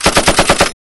FX - ratatata.wav